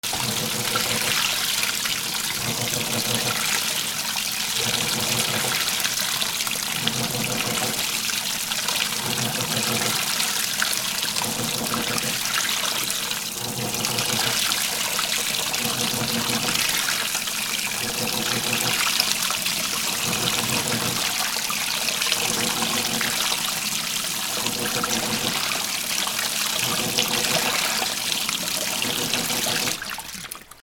洗濯機 3 注水 洗濯槽回転
/ M｜他分類 / L30 ｜水音-その他
『ウィーン パシャ』